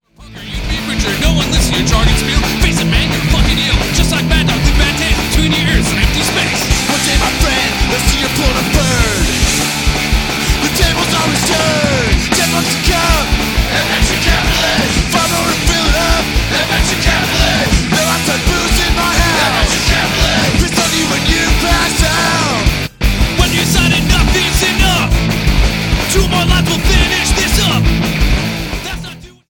Thirteen in your face punk rock gems.